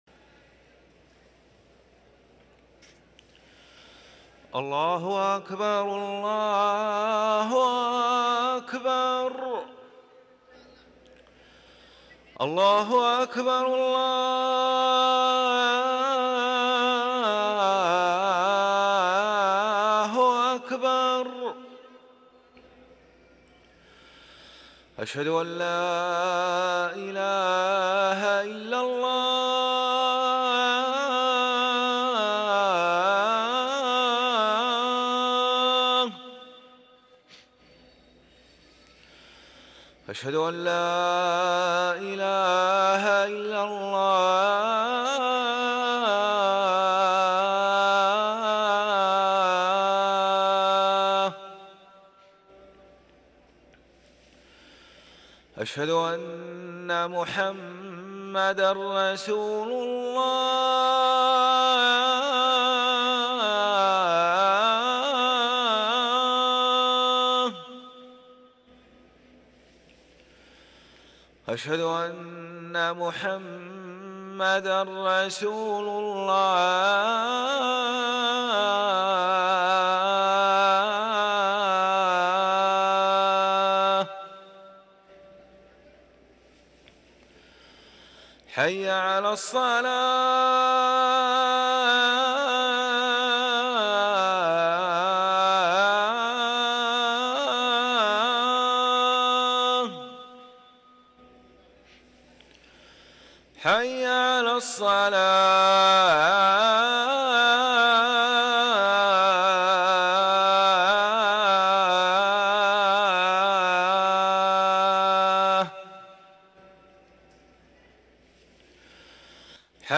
أذان العصر للمؤذن